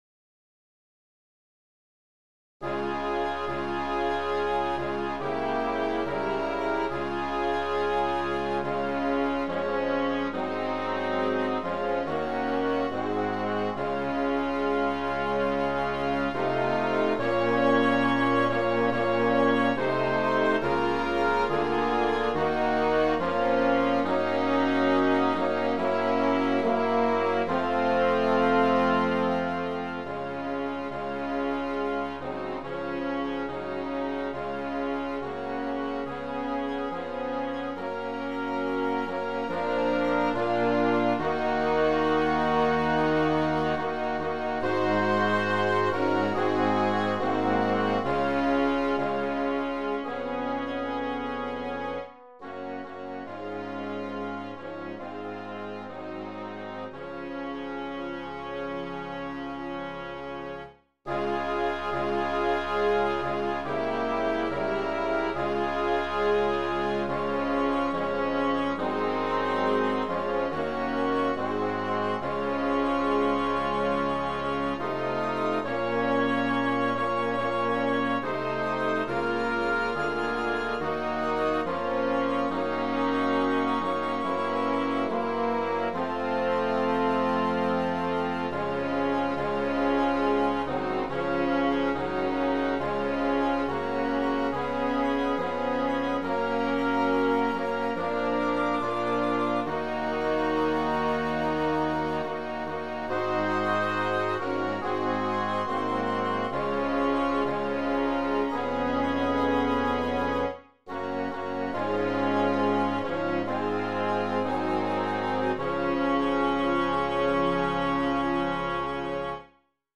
It lets you export to a WAV file and then I use my MIDI to WAV program to convert it to an MP3.
HYMN MUSIC